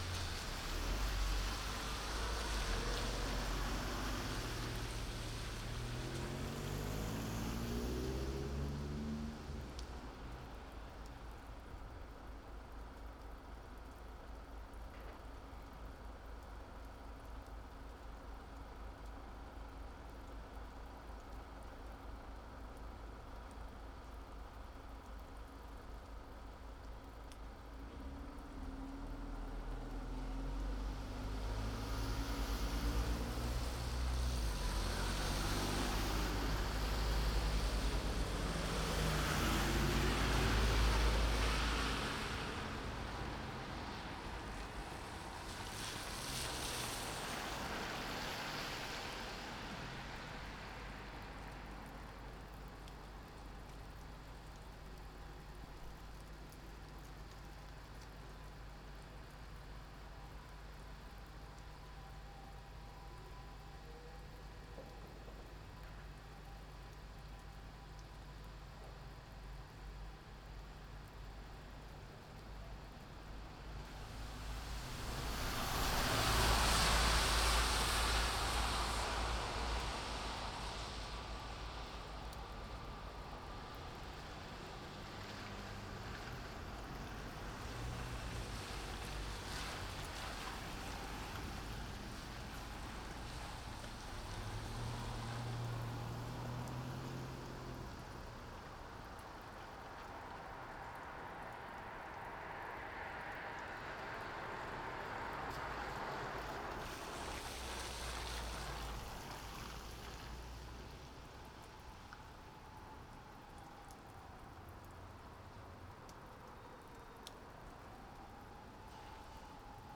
雨なので自宅玄関前で録音
F6+AT822
録音ファイル　ステレオ